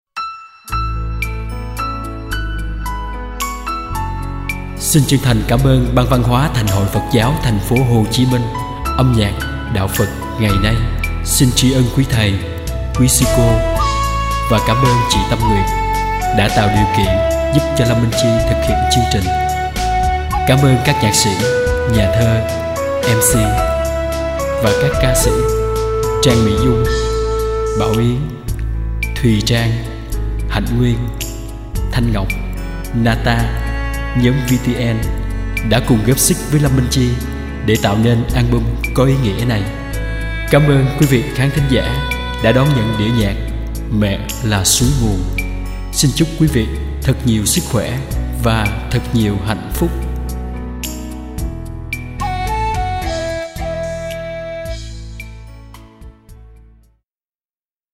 Tân Nhạc